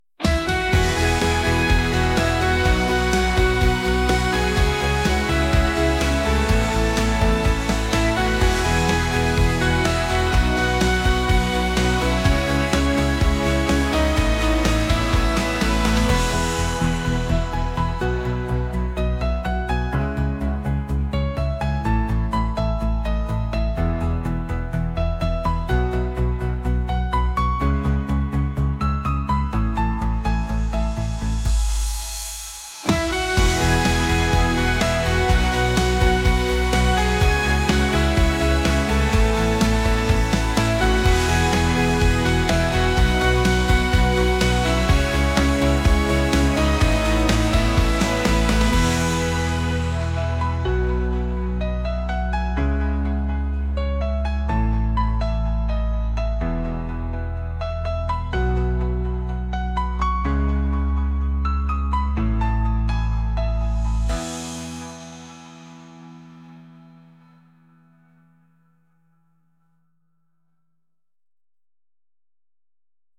pop | acoustic | indie